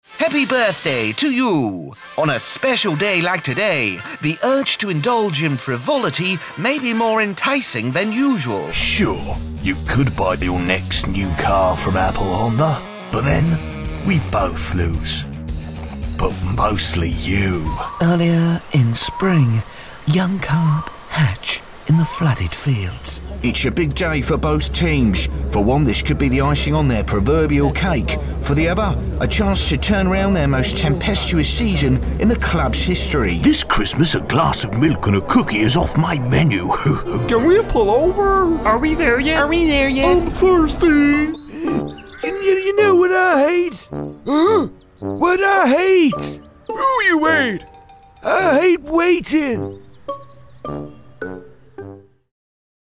Young, Professional, British Male Voice Artist.
britisch
Sprechprobe: Sonstiges (Muttersprache):